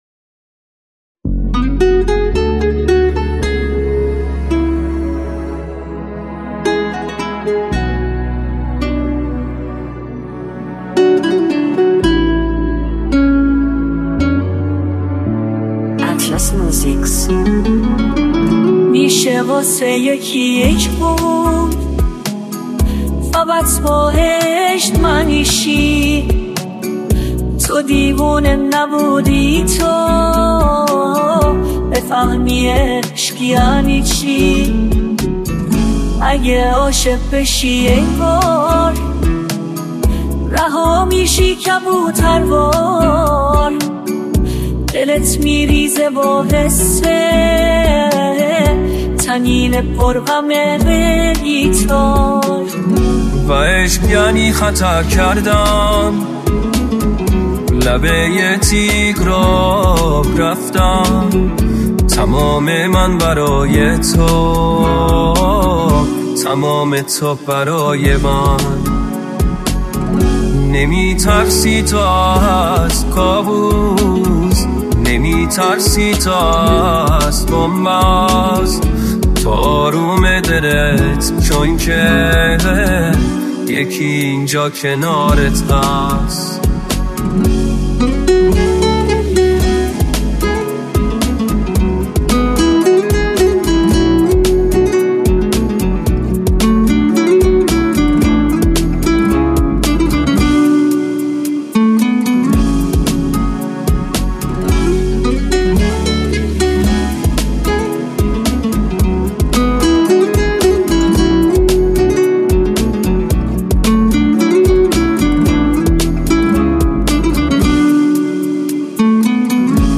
دانلود آهنگ پاپ ایرانی دانلود آهنگ های هوش مصنوعی